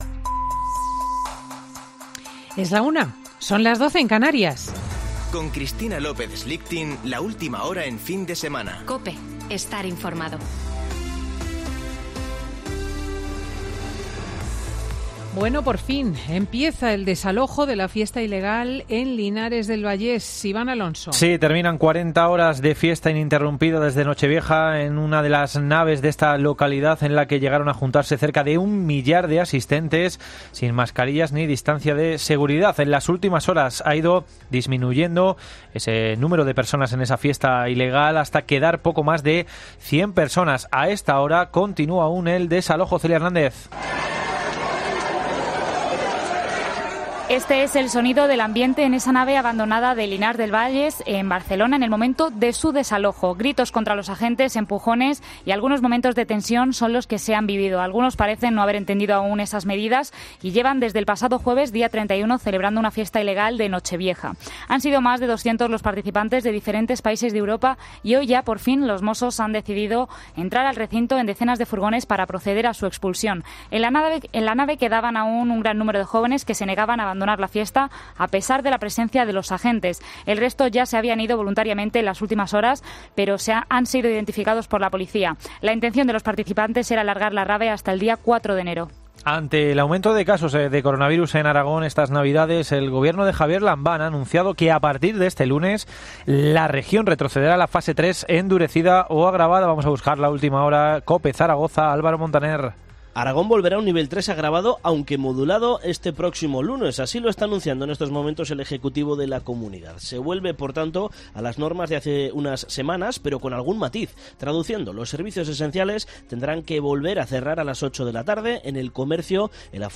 Boletín de noticias COPE del 2 de enero de 2021 a las 13.00 horas